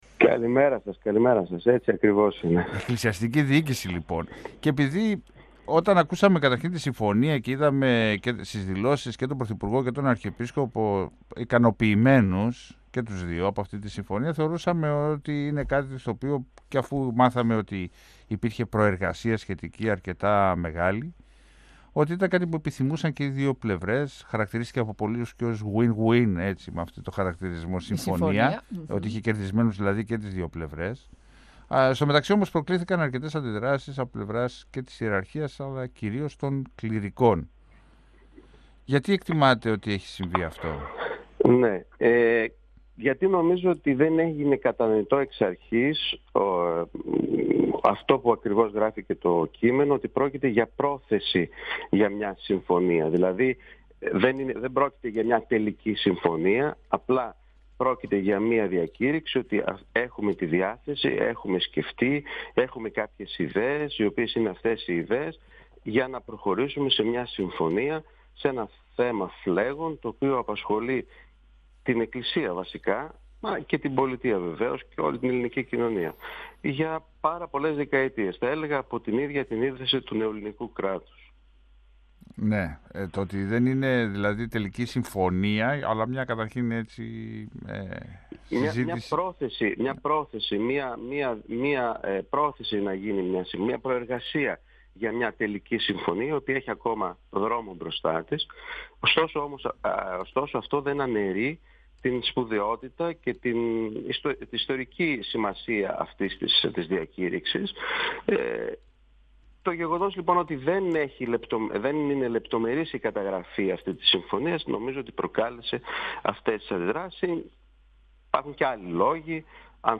Πρόσθεσε ότι η συζήτηση πρέπει να συνεχιστεί σε ήπιους τόνους χωρίς εντάσεις. 102FM Συνεντεύξεις ΕΡΤ3